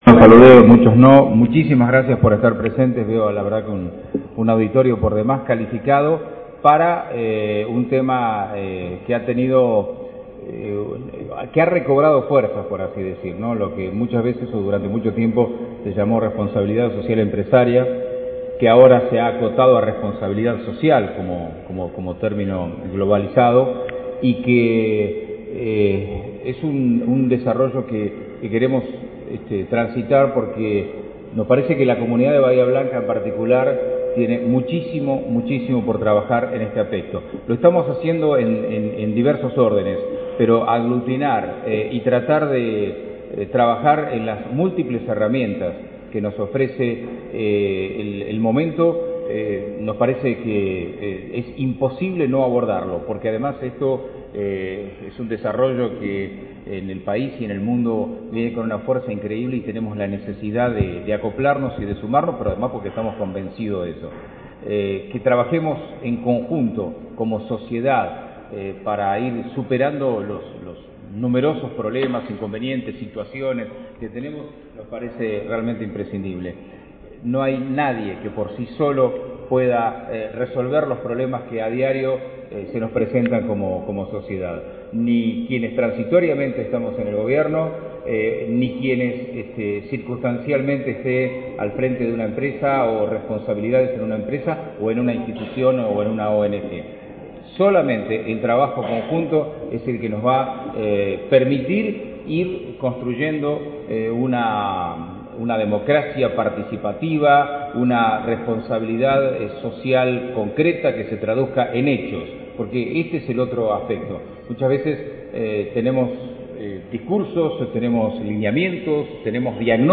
Gay-apertura-Jornada-de-Responsabilidad-Social-y-Sostenibilidad-07-11.mp3